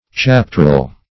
Chaptrel \Chap"trel\, n.